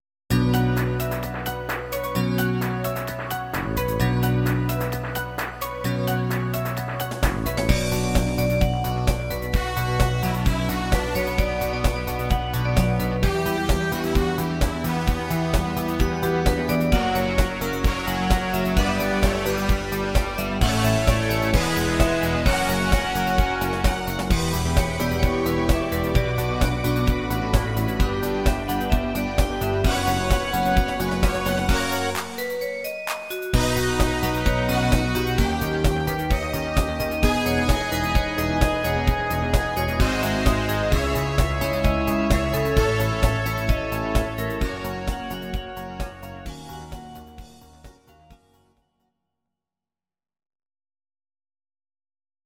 These are MP3 versions of our MIDI file catalogue.
dance mix